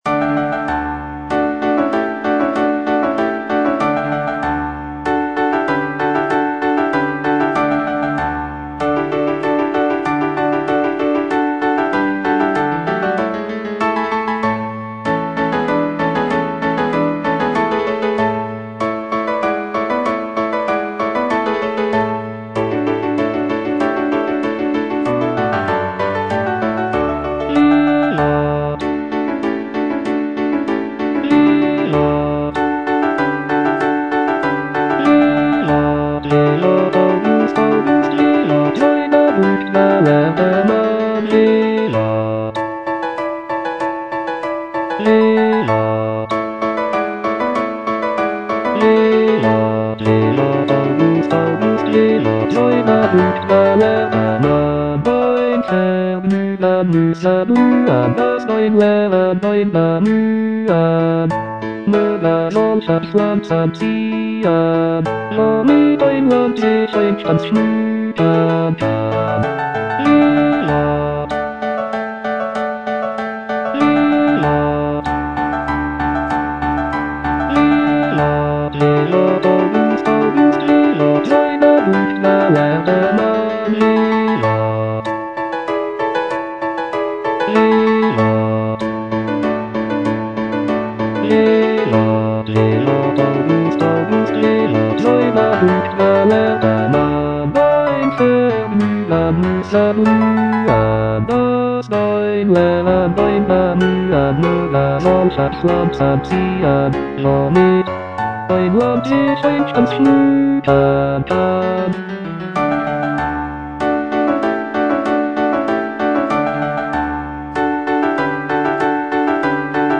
The music is lively and celebratory, with intricate counterpoint and virtuosic vocal lines.